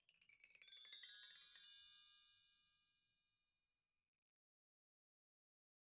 BellTree_Stroke4_v1_Sum.wav